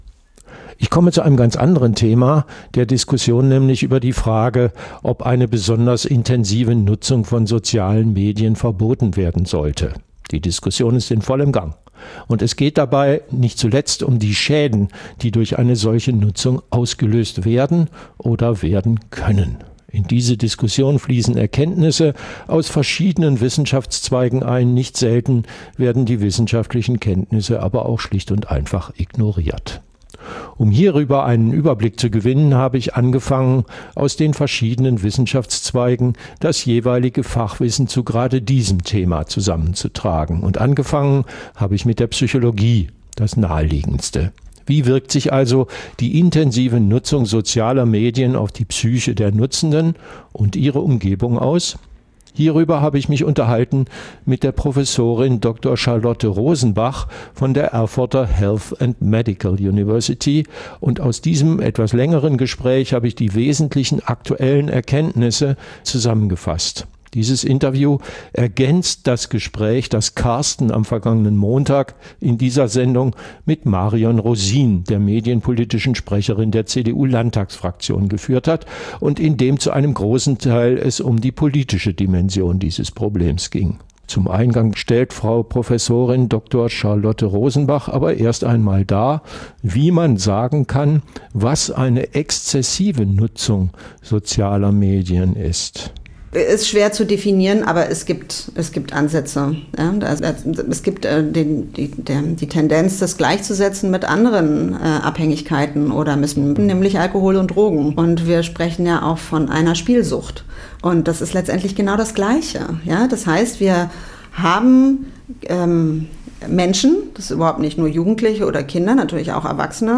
Nutzung sozialer Medien aus psychologischer Sicht - Gespräch